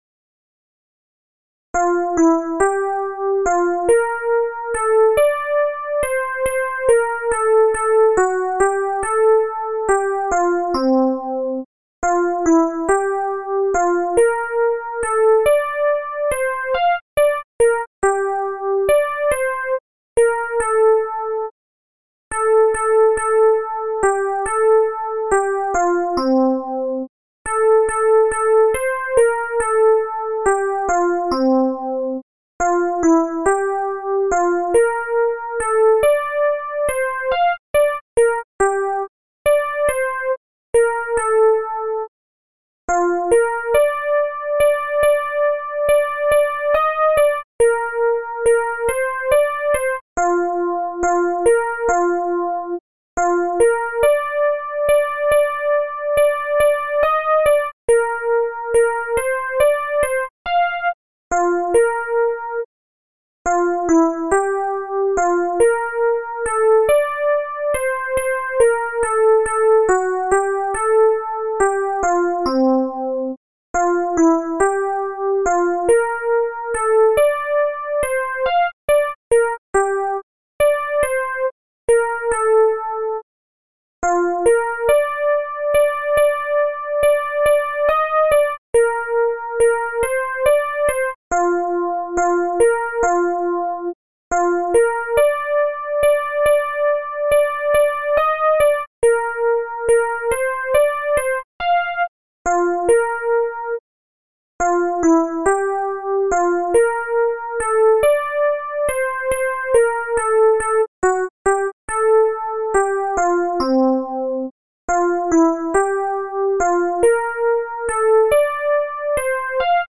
Soprani
barcarola_soprani.MP3